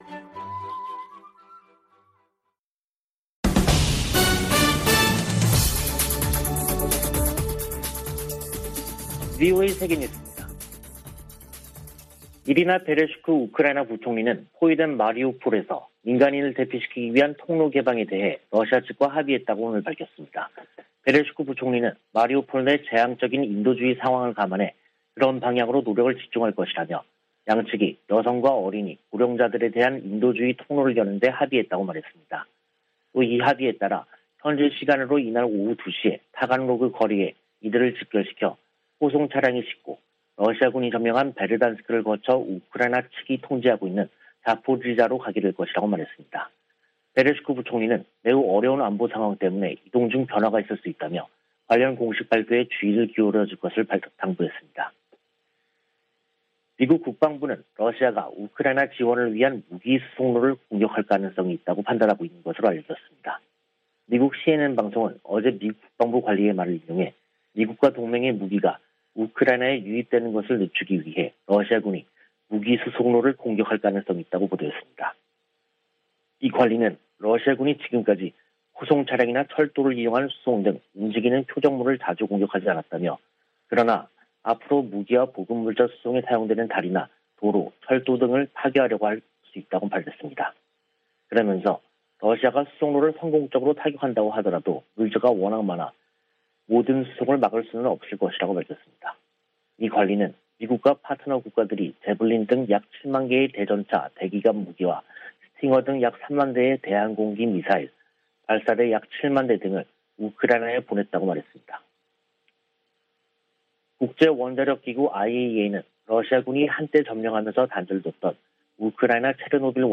VOA 한국어 간판 뉴스 프로그램 '뉴스 투데이', 2022년 4월 20일 3부 방송입니다. 미 국무부는 북한이 도발을 계속하면 상응 조치를 이어갈 것이라고 경고했습니다.